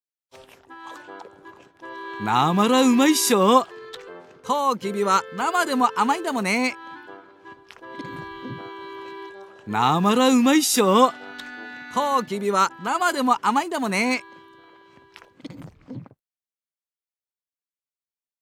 北海道弁を読み上げる“新感覚かるた”！
BGM・効果音付きで楽しさ倍増